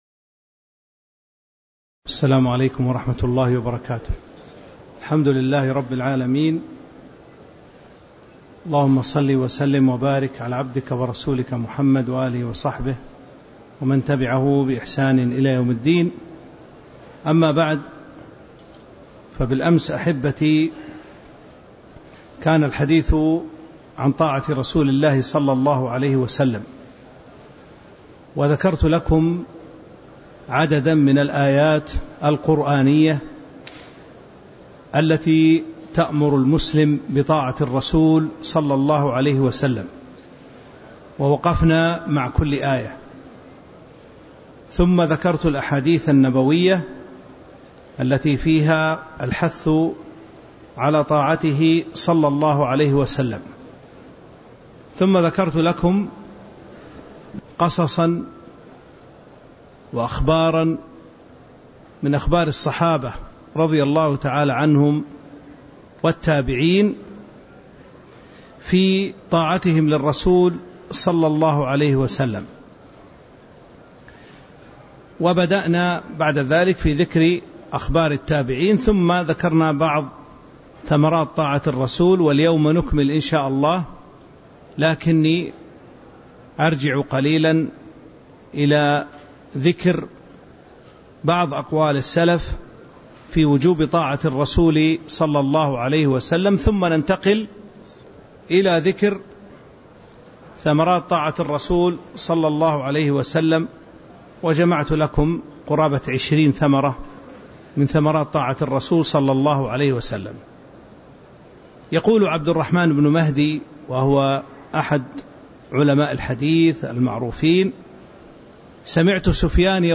تاريخ النشر ١٣ ذو القعدة ١٤٤٥ هـ المكان: المسجد النبوي الشيخ